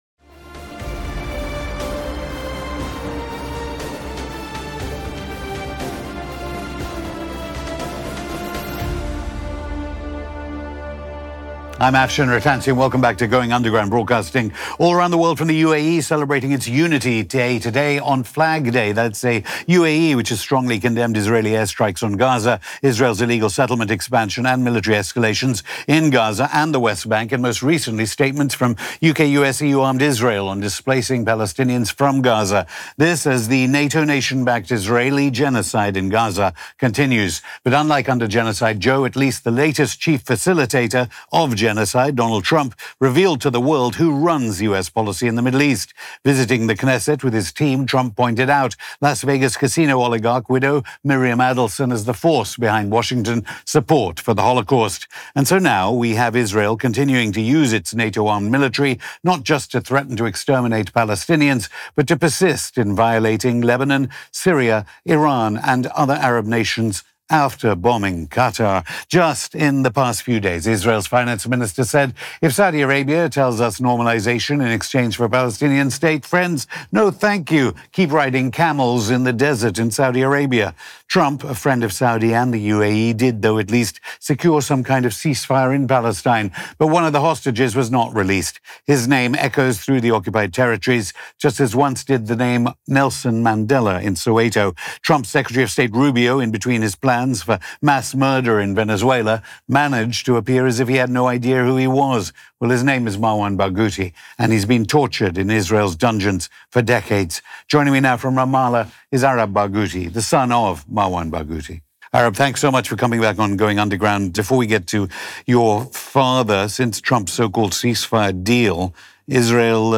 Interview
Going Underground Hosted by Afshin Rattansi